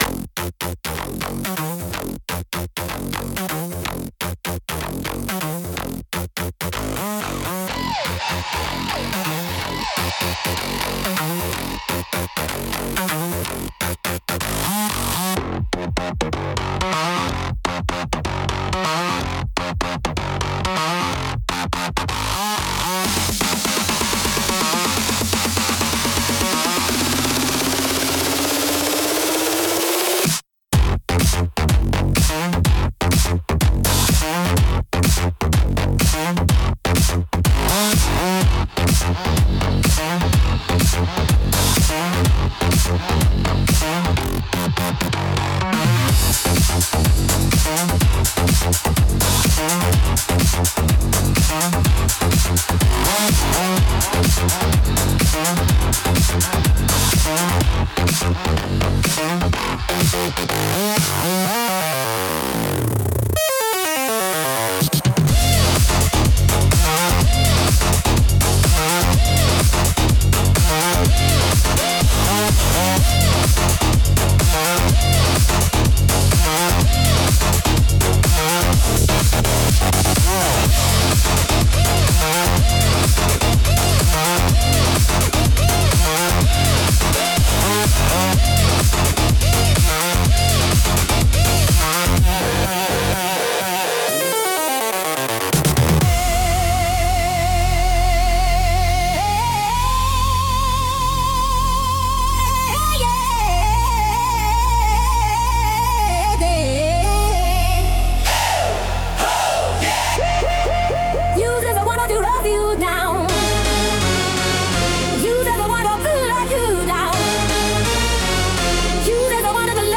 Instrumental - Rave of the Damned